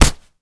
kick_soft_jab_impact_02.wav